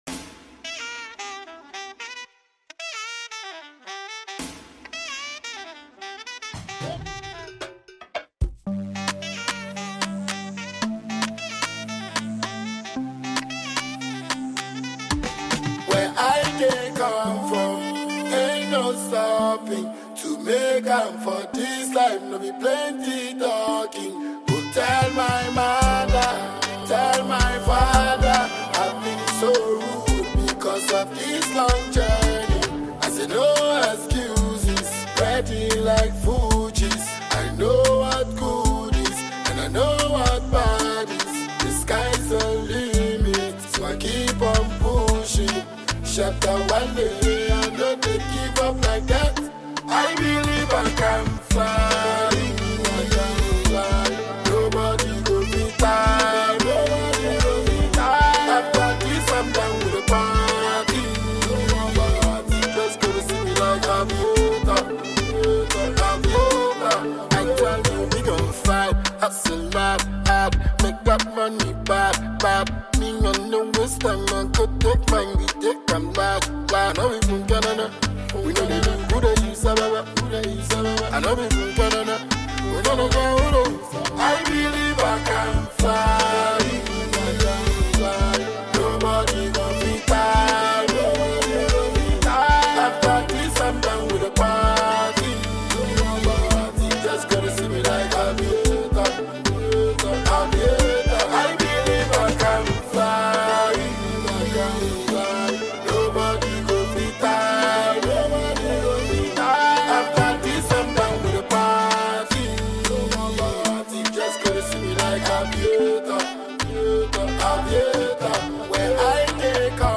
Ghana Music
Ghanaian dancehall artist